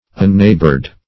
Unneighbored \Un*neigh"bored\, a.